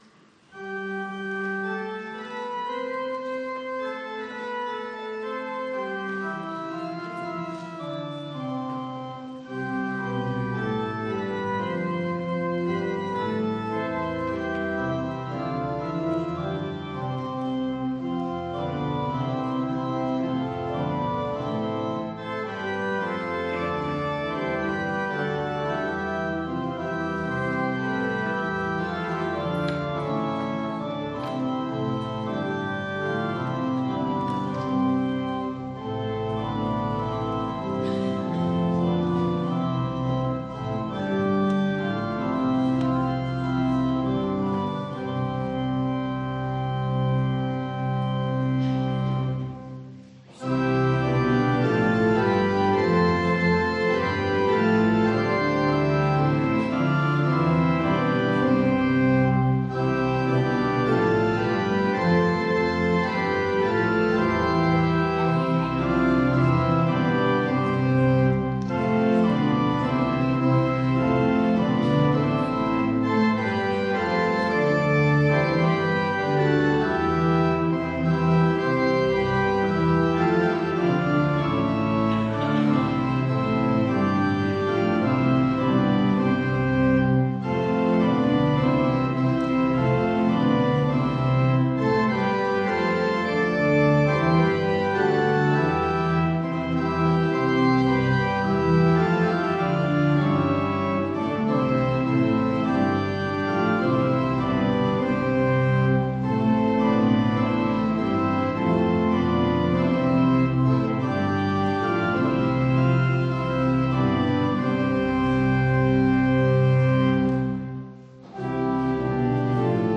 Gottesdienst am 30.05.2021